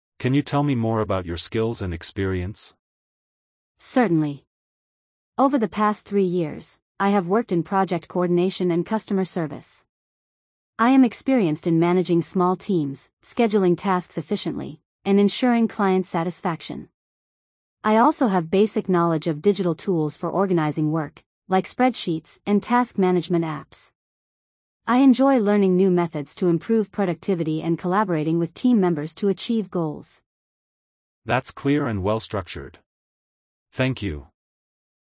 🤝 The candidate describes her skills and experience during an interview.